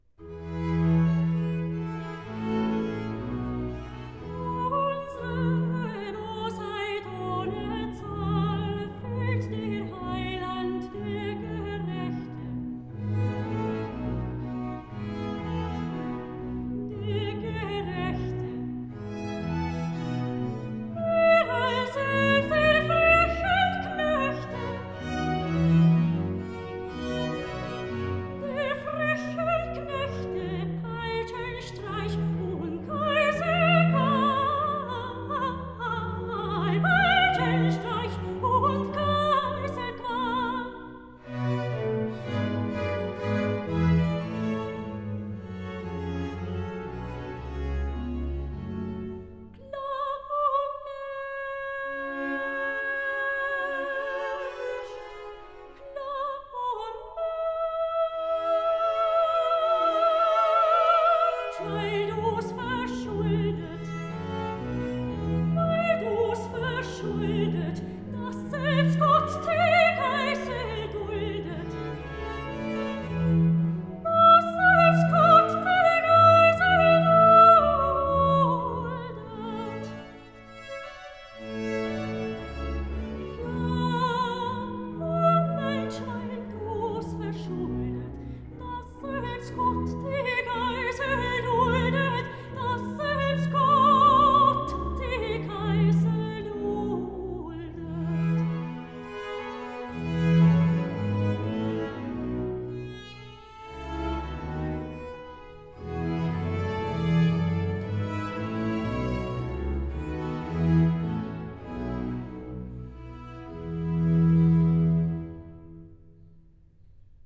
02 - Aria soprano Unsere Bosheit ohne Zahl